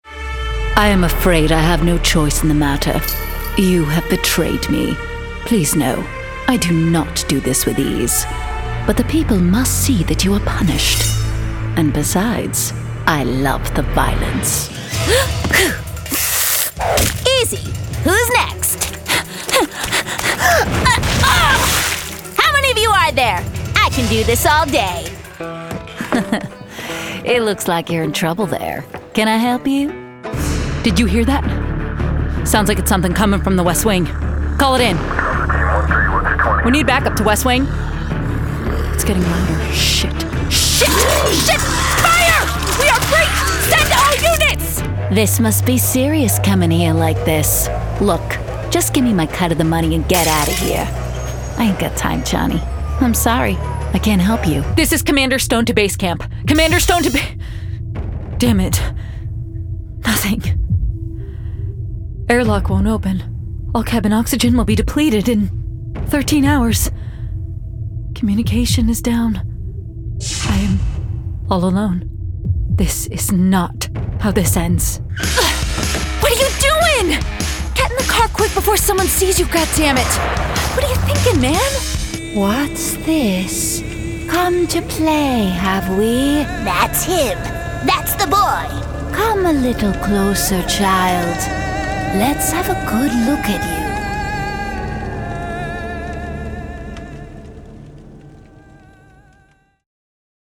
new york : voiceover : animation